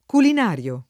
vai all'elenco alfabetico delle voci ingrandisci il carattere 100% rimpicciolisci il carattere stampa invia tramite posta elettronica codividi su Facebook culinario [ kulin # r L o ] agg.; pl. m. -ri (raro, alla lat., -rii )